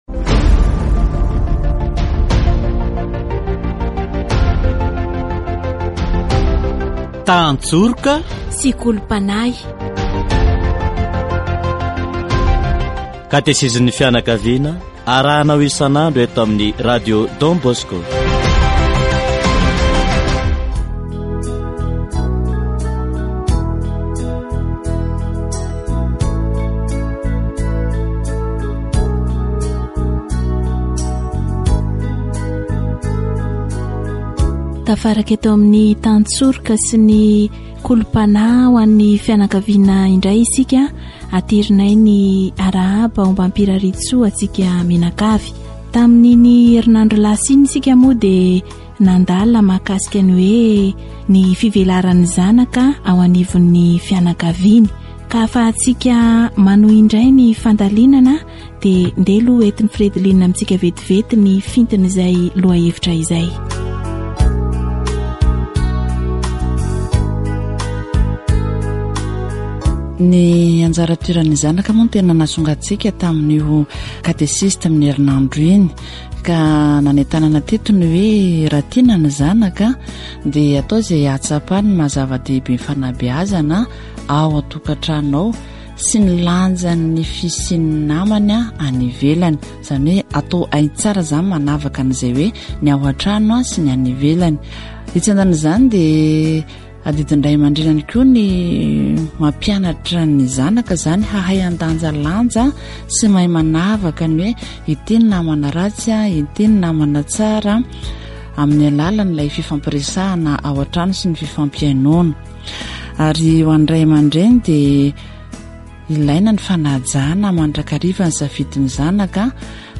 Catéchèse sur la Discussions à la maison